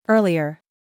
This is an accented sound
The first syllable is pronounced higher than the second syllable.
3. earlier /ɚ:liɚ/